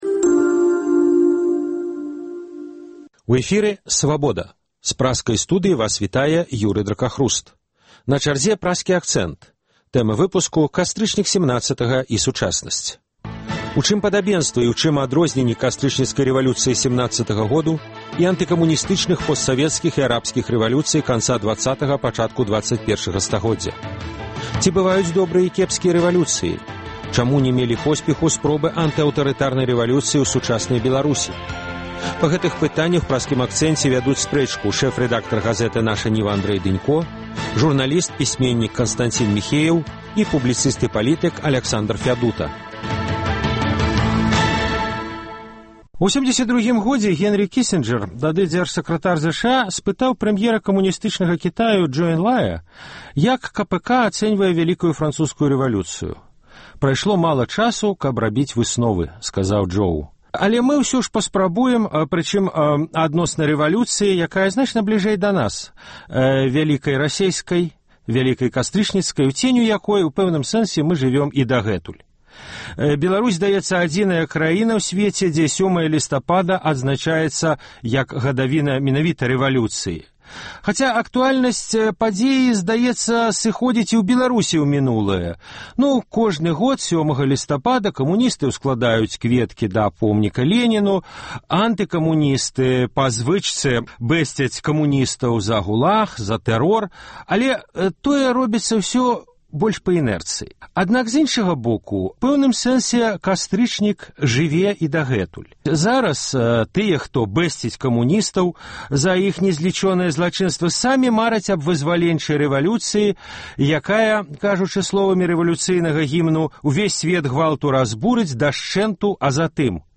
Чаму ня мелі посьпеху спробы антыаўтарытарнай рэвалюцыі ў сучаснай Беларусі? На гэтую тэму вядуць спрэчку